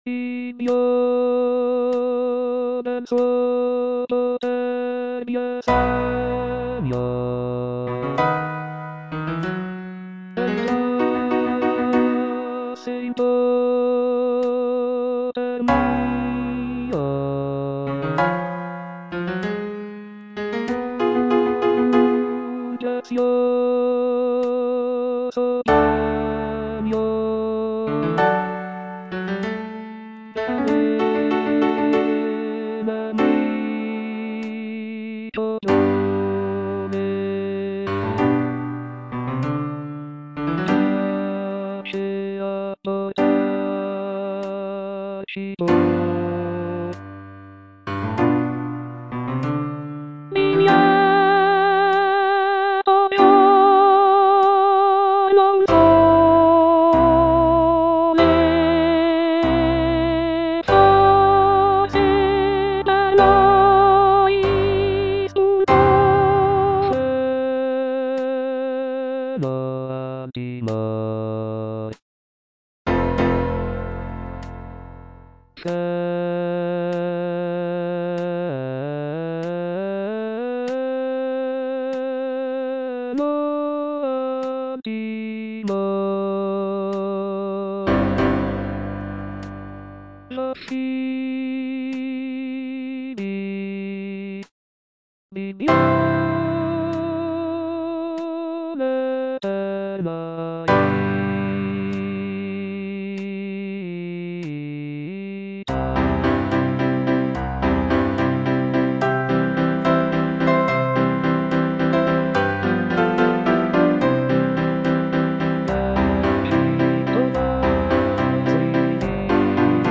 G. VERDI - DI LIETO GIORNO UN SOLE FROM "NABUCCO" Di lieto giorno un sole (soprano III) (Voice with metronome) Ads stop: